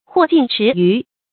禍近池魚 注音： ㄏㄨㄛˋ ㄐㄧㄣˋ ㄔㄧˊ ㄧㄩˊ 讀音讀法： 意思解釋： 猶言殃及池魚。比喻無辜而受害。